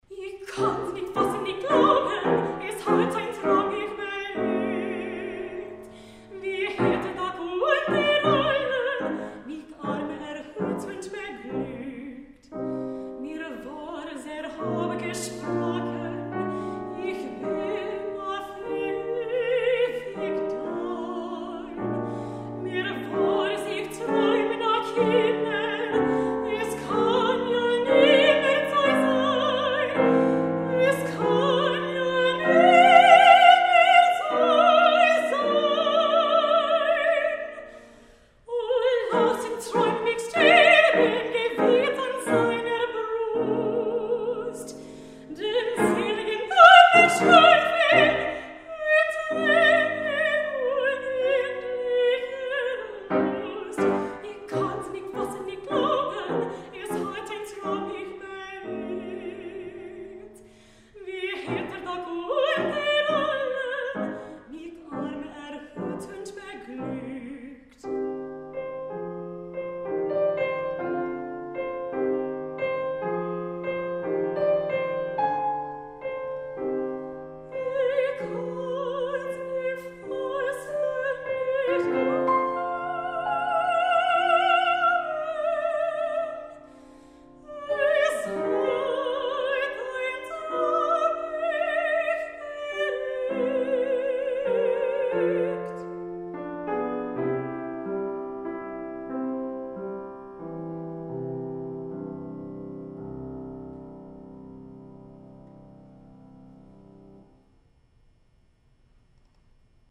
Mezzo-Soprano
Senior Recital